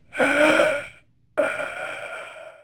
monster.ogg